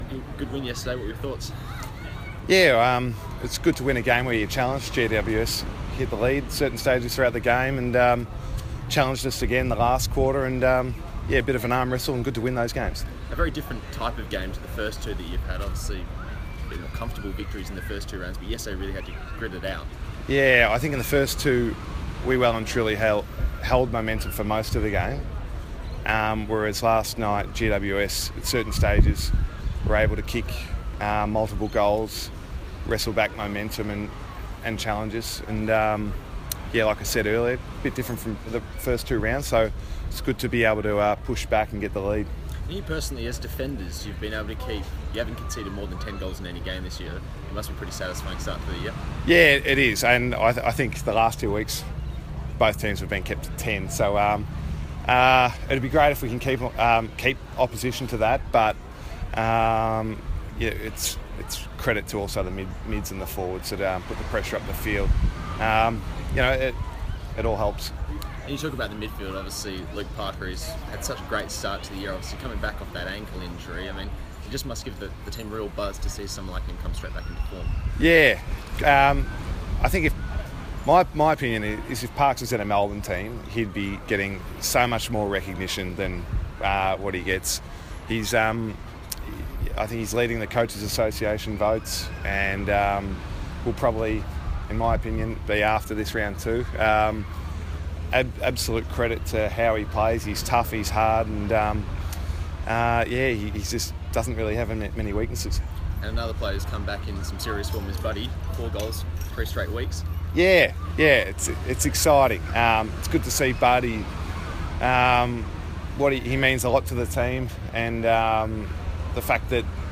Ted Richards speaks to the media at Bondi beach recovery on Sunday.